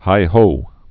(hīhō, hā-)